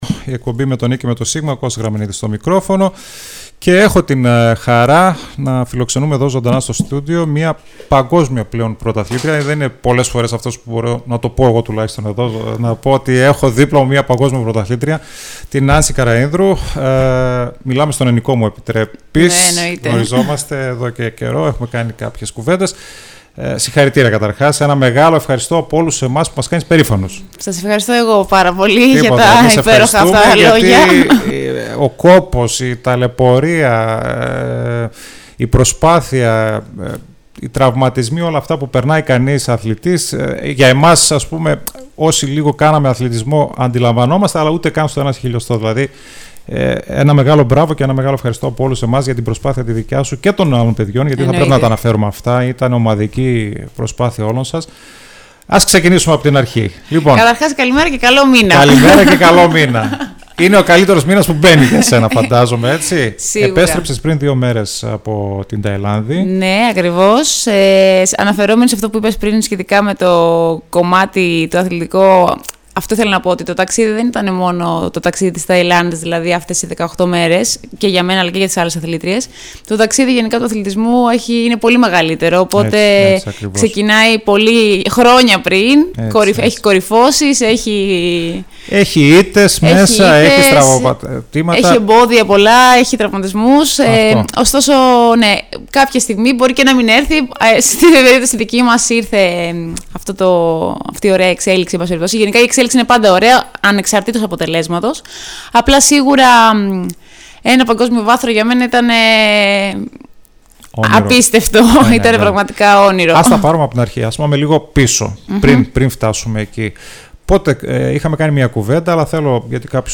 μίλησε σήμερα στην εκπομπή του style 100